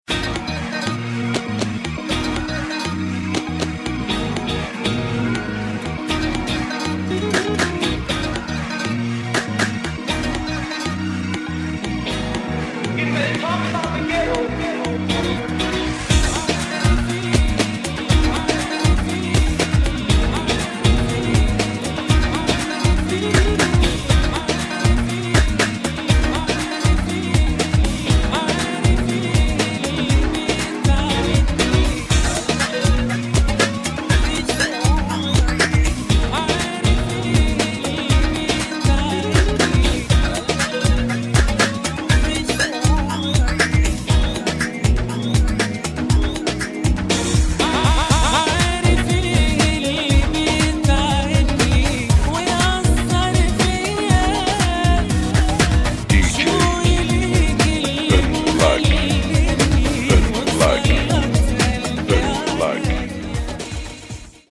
Arabic Remix